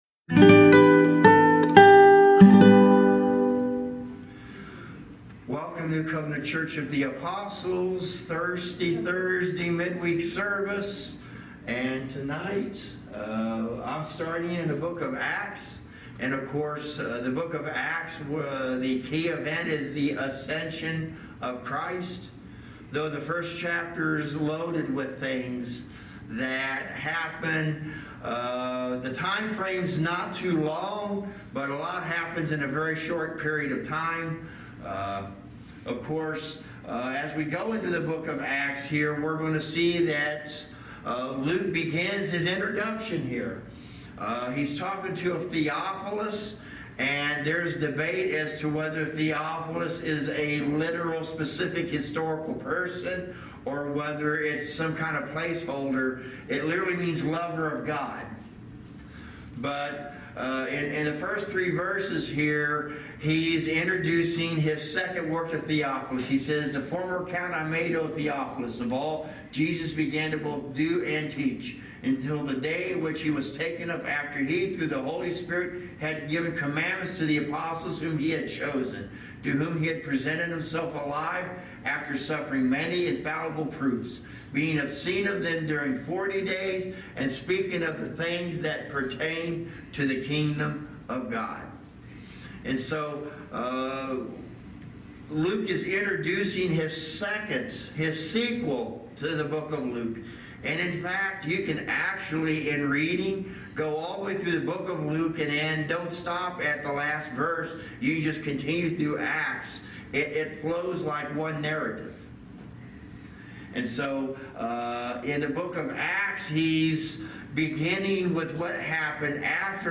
Service Type: Thirsty Thursday Midweek Teaching